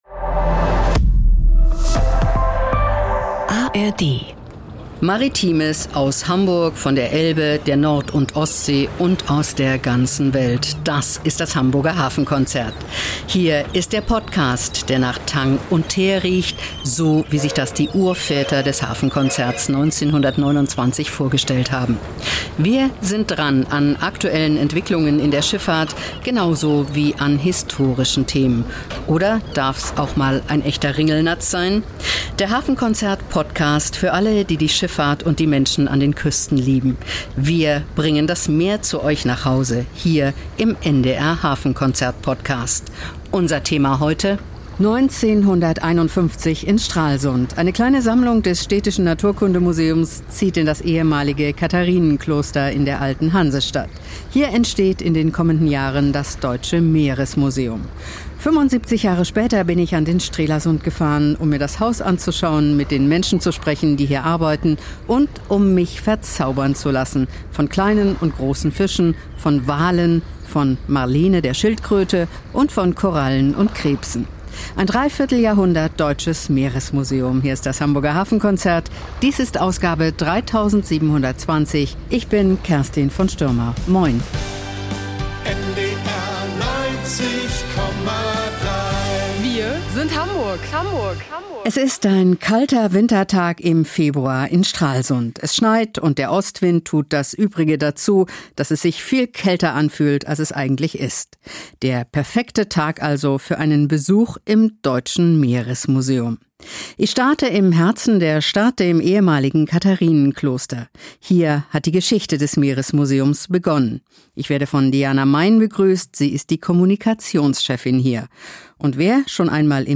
Hier, mitten in Stralsund, wird das Meer erfahrbar – mit Walen unter dem Dach des ehemaligen Klosters, Korallenriffen im Kellergewölbe und Forschungslaboren hinter historischen Mauern. Das Deutsches Meeresmuseum feiert 75 Jahre – und ist heute weit mehr als ein Ausstellungshaus.